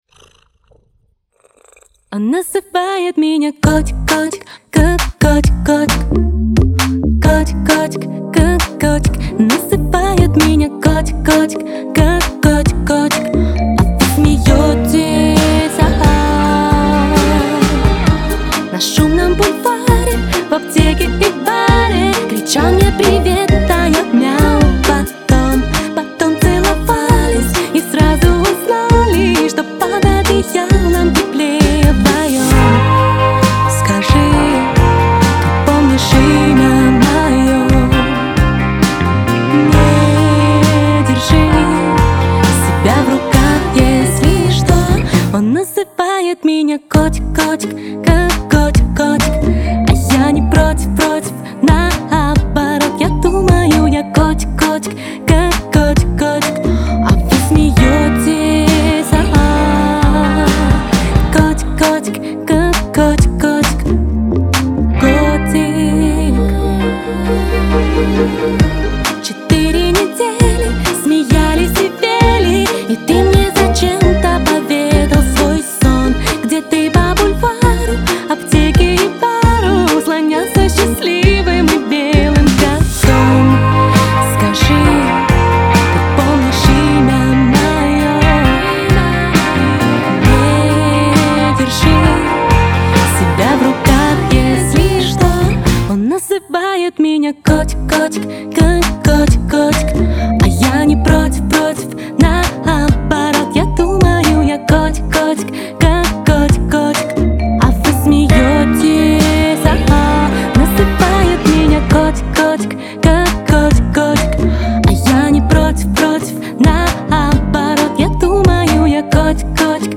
запоминающимся мелодичным припевом и ритмичной аранжировкой